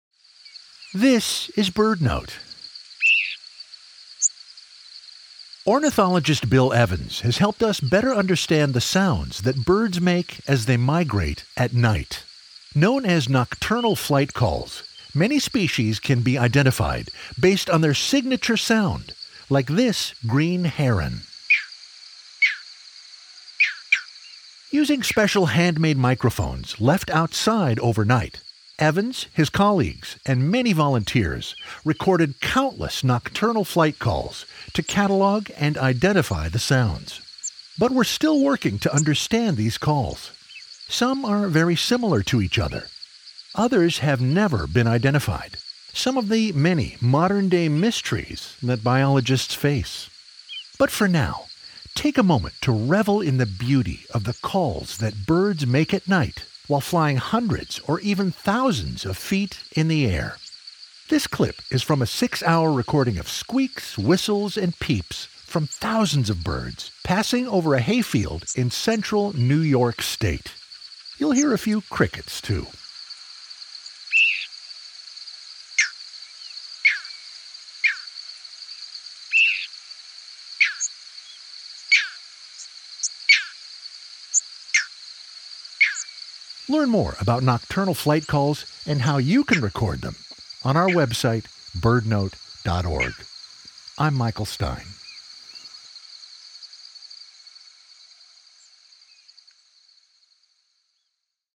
Known as nocturnal flight calls, many species can be identified based on their signature sound.
Let’s take a moment to revel in the beauty of the calls that birds make as they fly high in the air.
BirdNote is sponsored locally by Chirp Nature Center and airs live everyday at 4 p.m. on KBHR 93.3 FM.